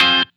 Track 08 - Guitar Stab OS 02.wav